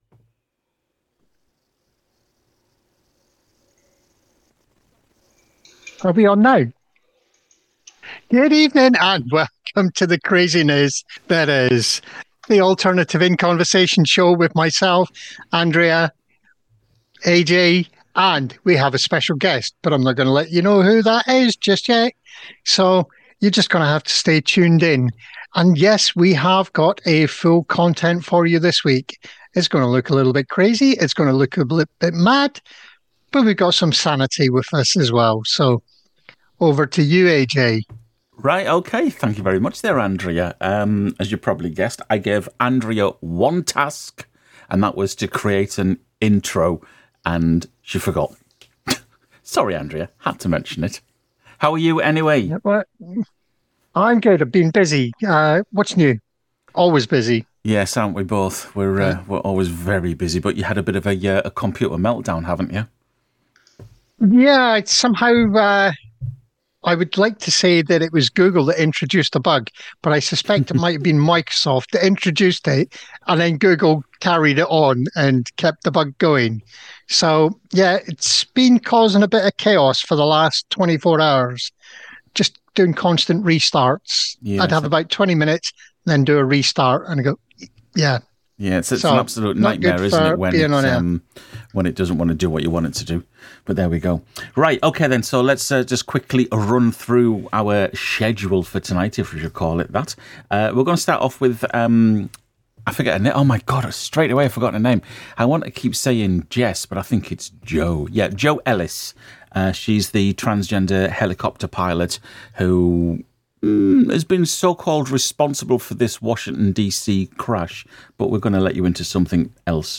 Oh wait, did I mention my shows are pre-recorded?